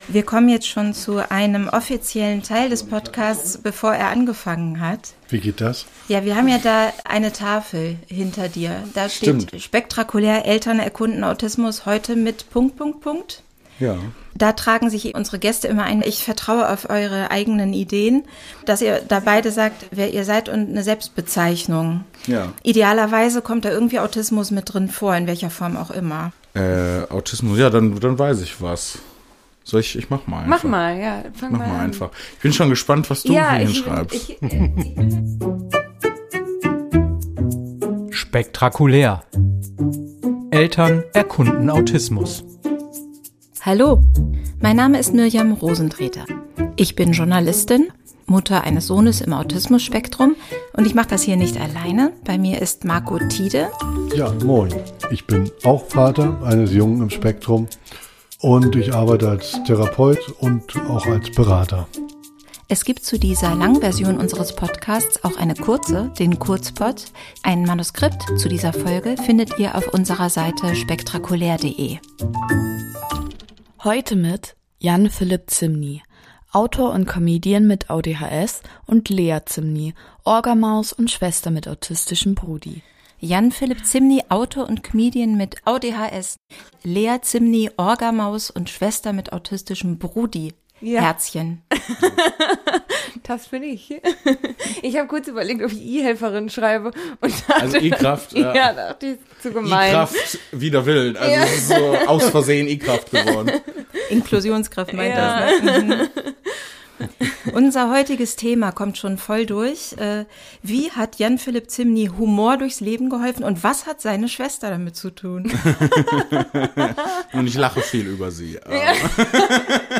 Und so auch zu uns ins Podcaststudio.